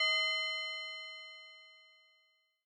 train crossing bell
bell cling crossing ding express impact locomotive passing sound effect free sound royalty free Voices